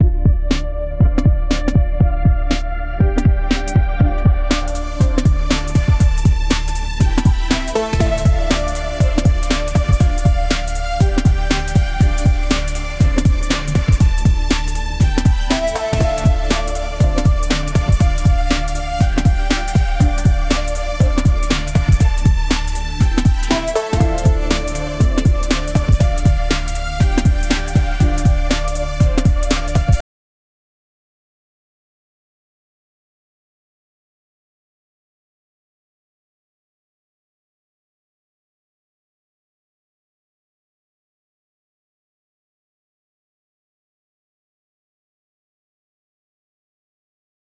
music-generation
sao_D_Major_pop_1.wav